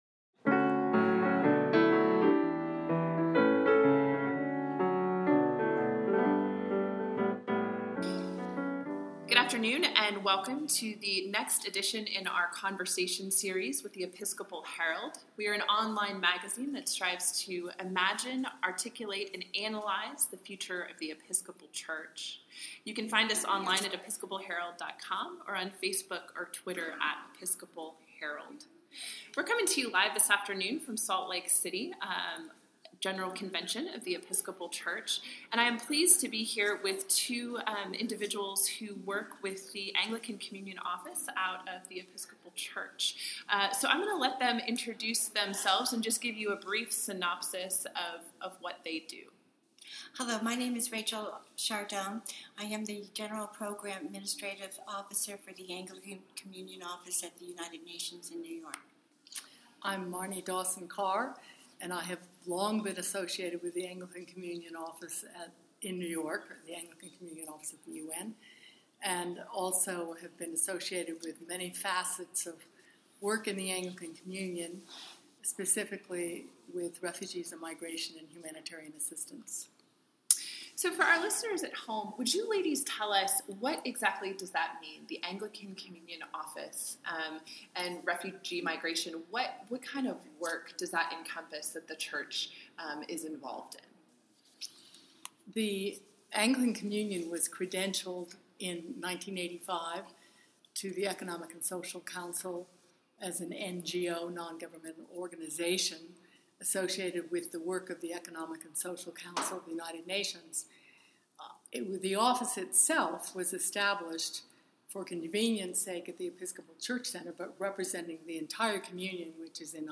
Sorry, again, for the background noise – General Convention is a busy place!